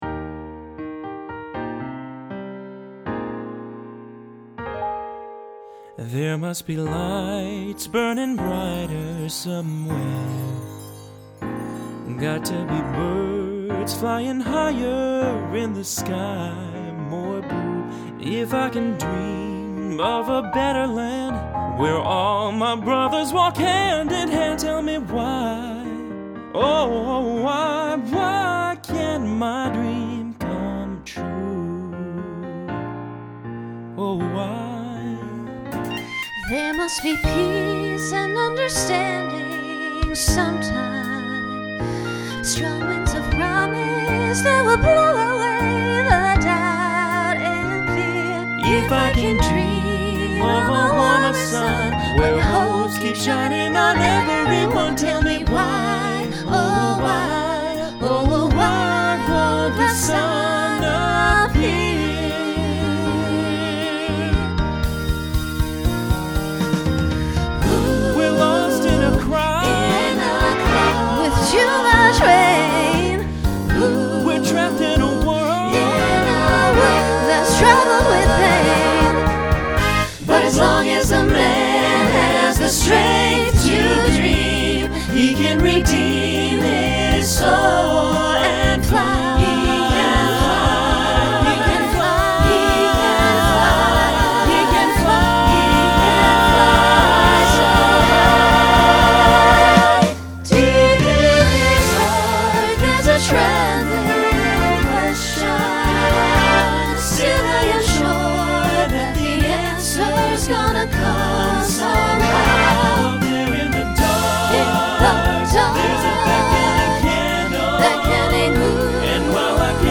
Genre Broadway/Film , Rock
Ballad , Solo Feature Voicing SATB